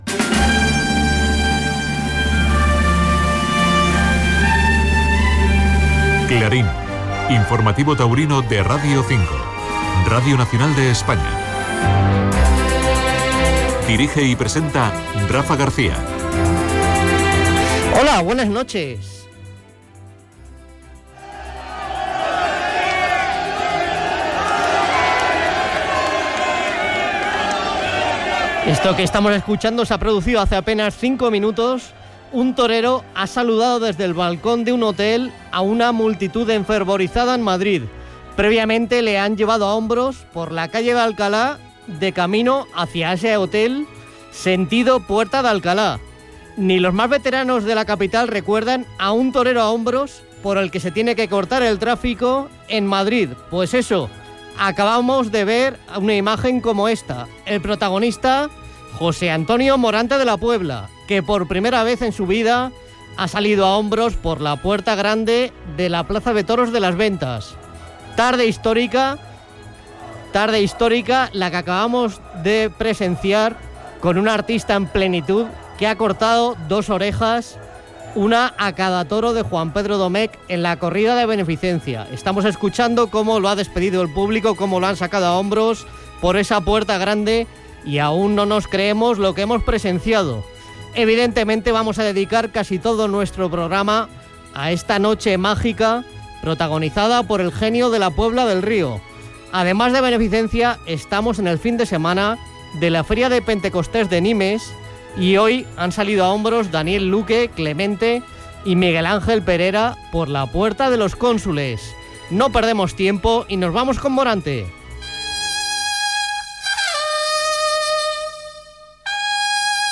Careta del programa
Contacte telefònic amb Sevilla i amb l'alcaldessa de La Puebla del Río. Informació de les corregudes de braus de Nimes. Tertúlia